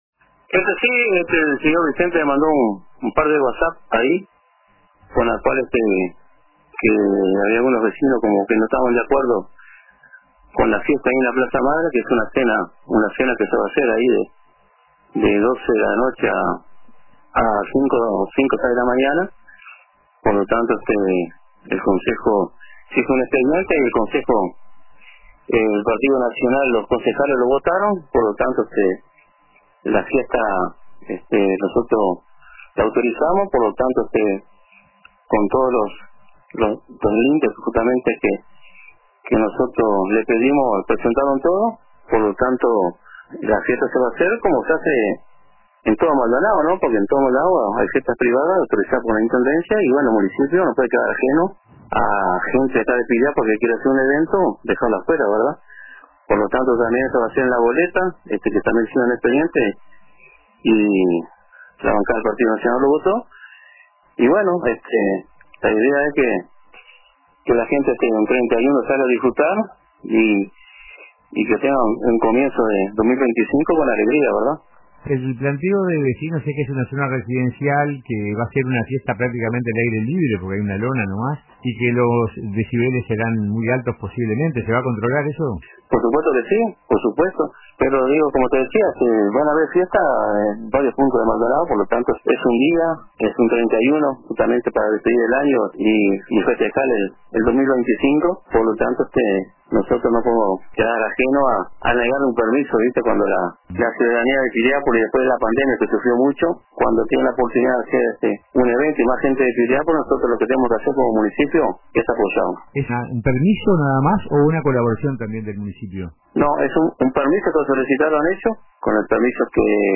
Por su parte, el alcalde del Municipio de Piriápolis, René Graña, fue entrevistado en el programa Radio con Todos de RBC y se refirió a la polémica: